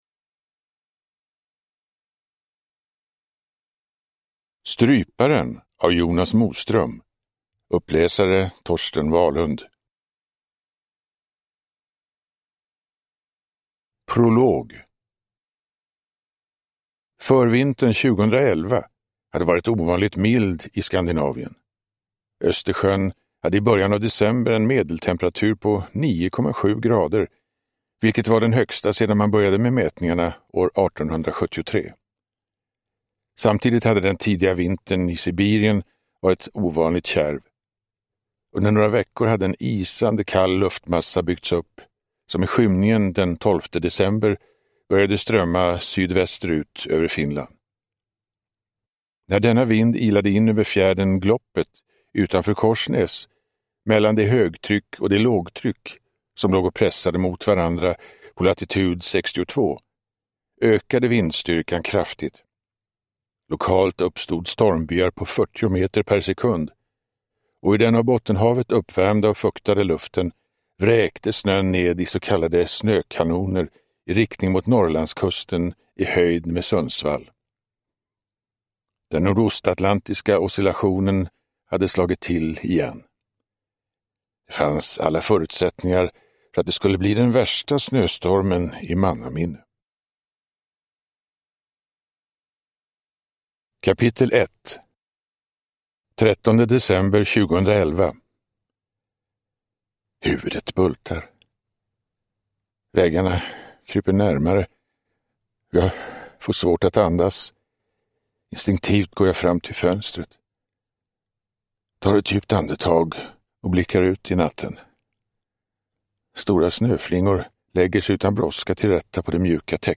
Stryparen – Ljudbok – Laddas ner
Uppläsare: Torsten Wahlund